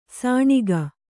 ♪ sāṇiga